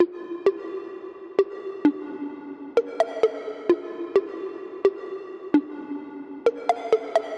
描述：EDM陷阱/未来贝斯/开关房弹拨引线循环
Tag: 130 bpm EDM Loops Synth Loops 1.24 MB wav Key : Unknown FL Studio